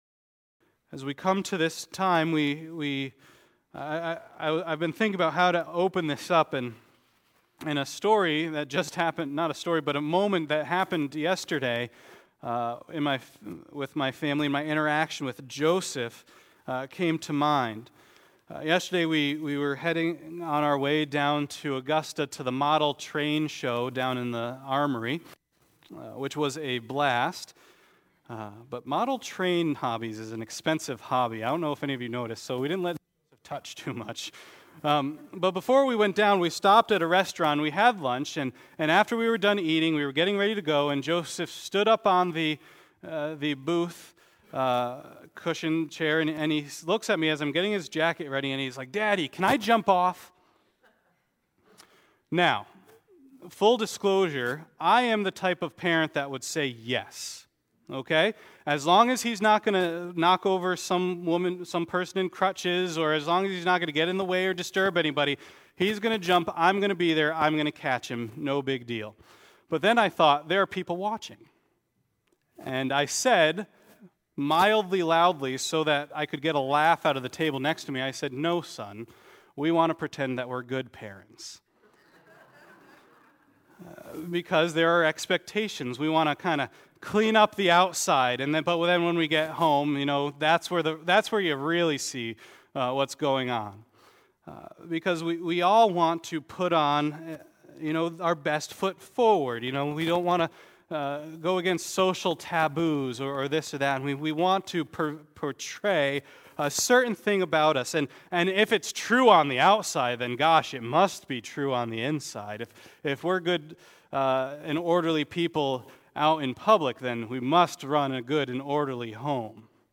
Matthew 12:43-45 Service Type: Sunday Worship « What Are We Looking For?